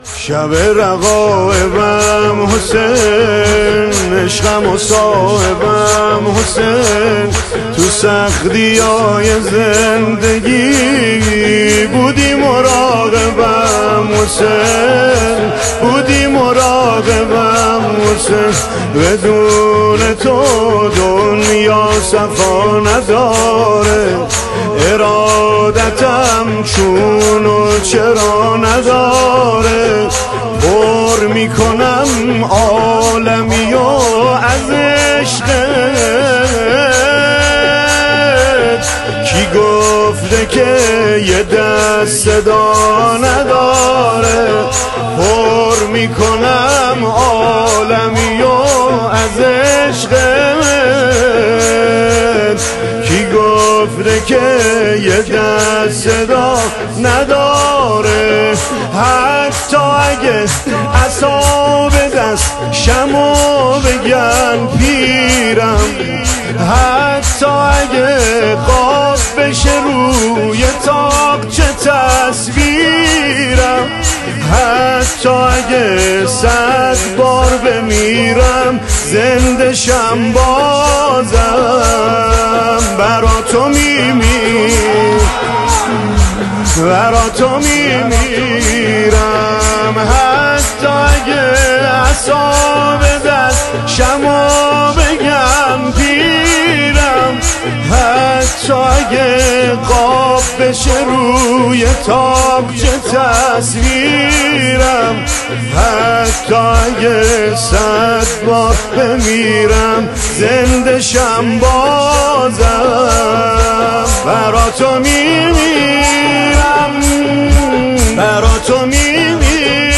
زمینه : شبِ آرزوهای زینب شب شام غریبانِپنجشنبه ۴ دی‌ماه ۱۴۰۴٤ رجب ۱٤٤٧